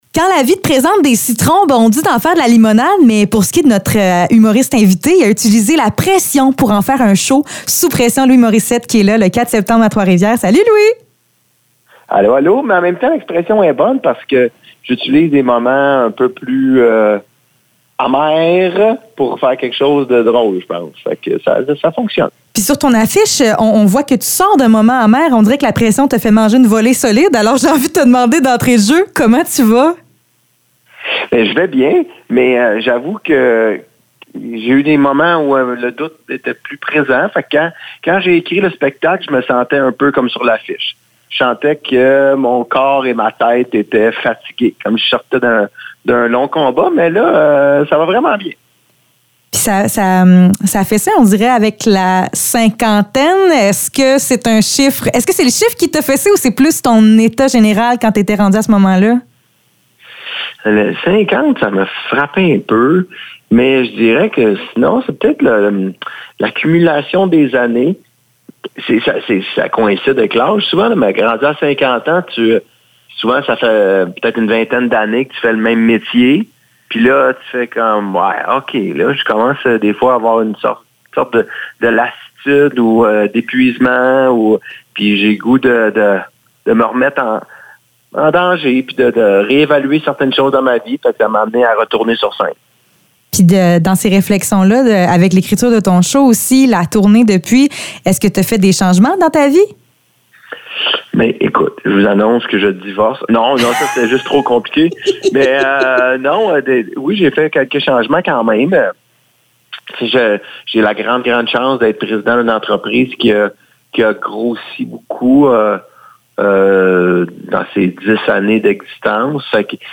Entrevue avec Louis Morissette